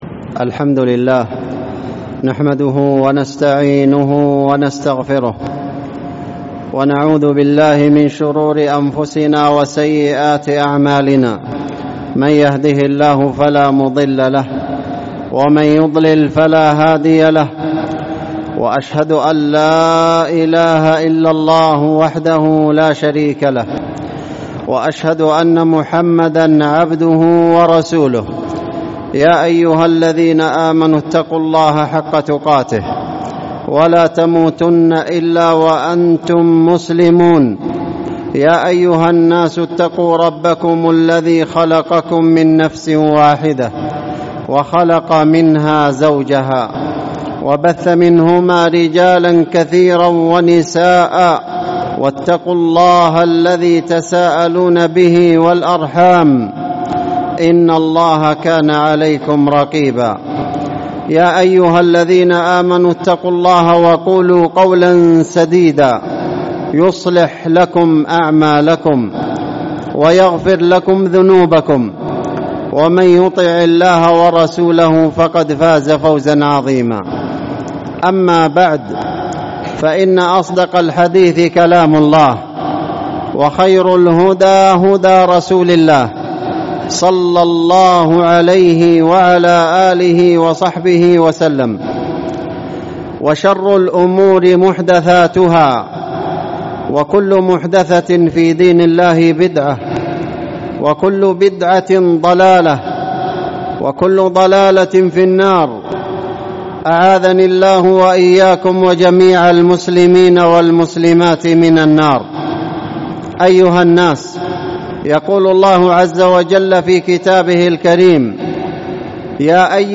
الصدق وبعض مجالاته | الخطب والمحاضرات